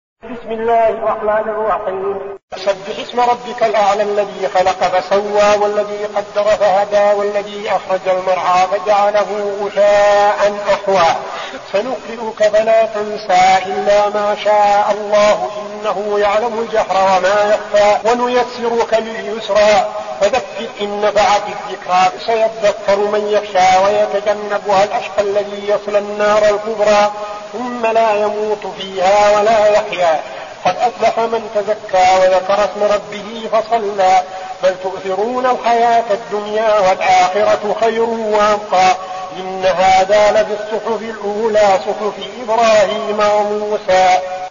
المكان: المسجد النبوي الشيخ: فضيلة الشيخ عبدالعزيز بن صالح فضيلة الشيخ عبدالعزيز بن صالح الأعلى